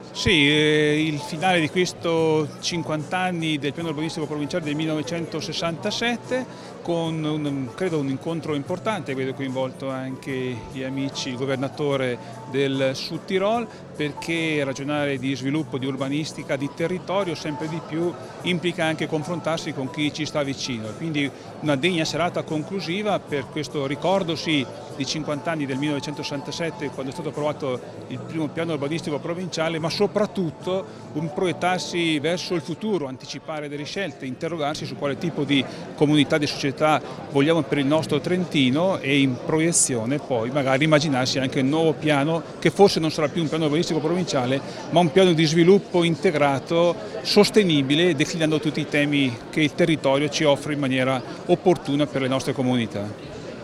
Scarica il file 2017-12-01 - MUSE - Intervista UGO ROSSI.mp3